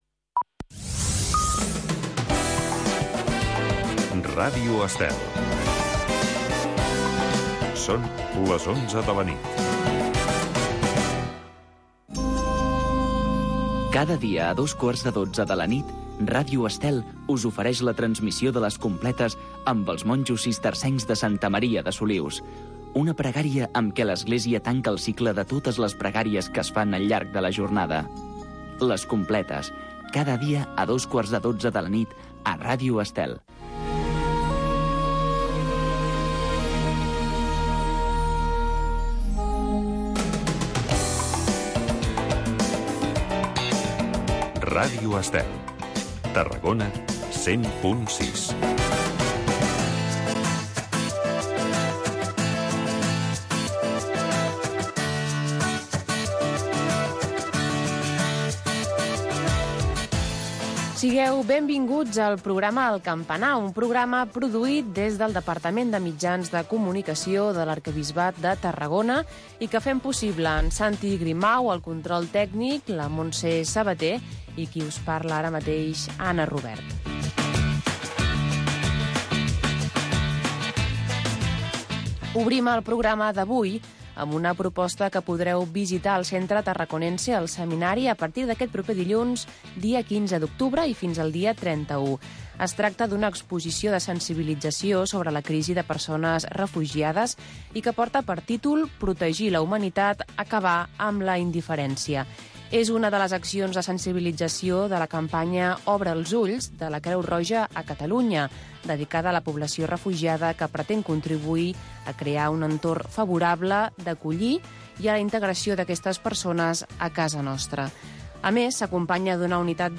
El campanar. Magazine d’actualitat de l'Arquebisbat de Tarragona, amb les activitats que duen a terme les seves delegacions i l’Arquebisbe